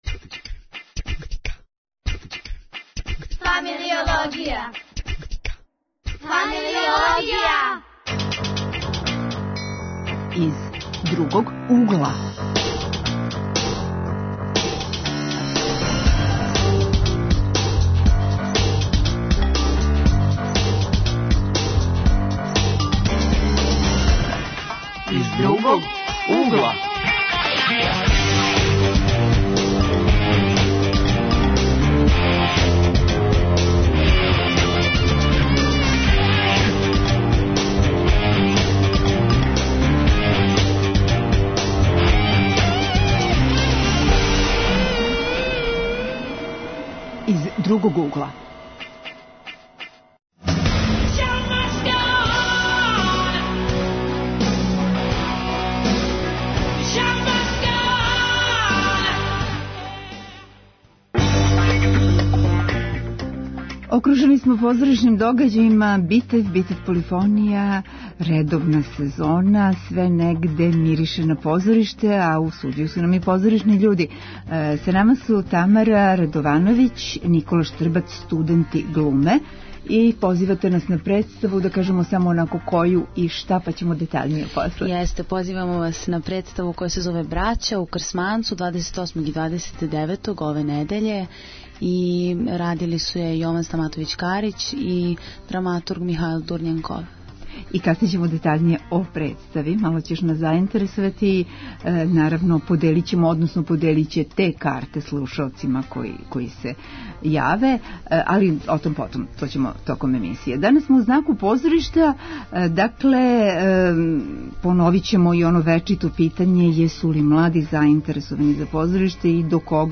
Данас смо у знаку позоришта из 'разноразних других углова'. У студију су студенти глуме који вас позивају на премијеру представе 'Браћа' у академском позоришту Бранко Крсмановић.